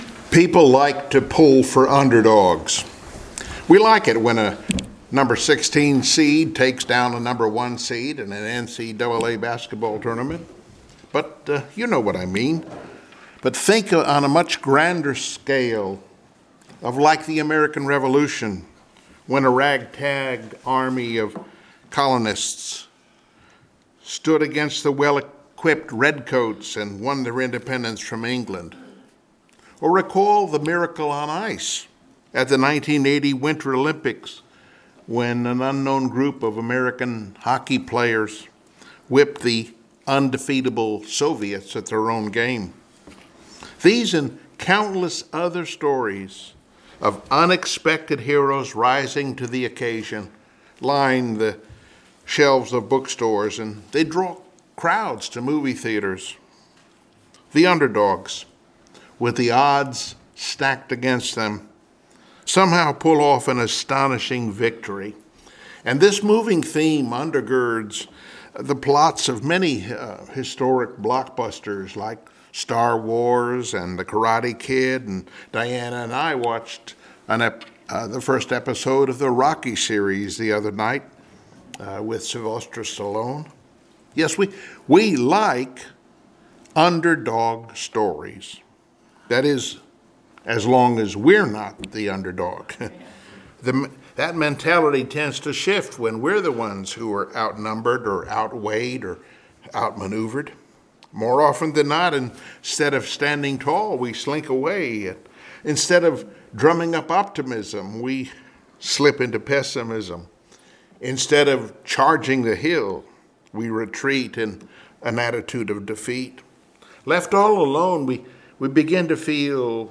Passage: 1 Samuel 17 Service Type: Sunday Morning Worship